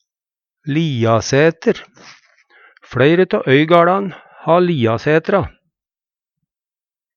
liasæter - Numedalsmål (en-US)